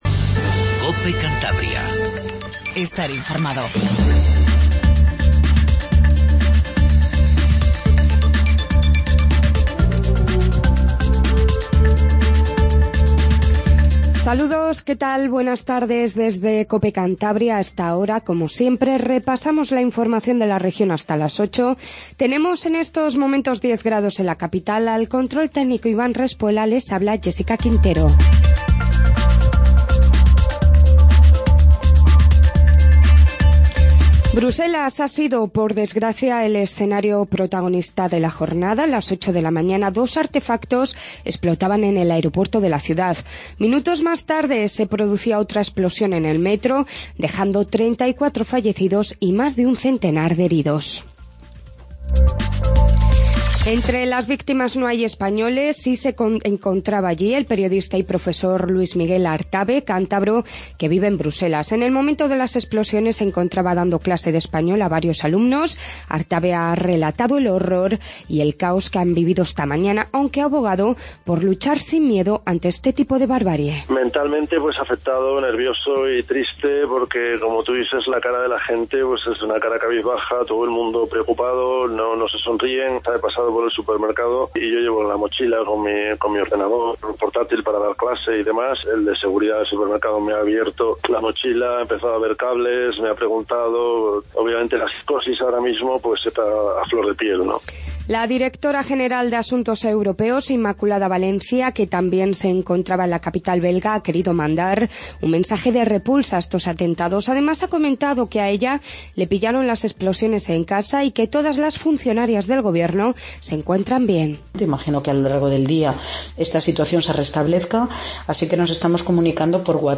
INFORMATIVO TARDE 19:50